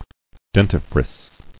[den·ti·frice]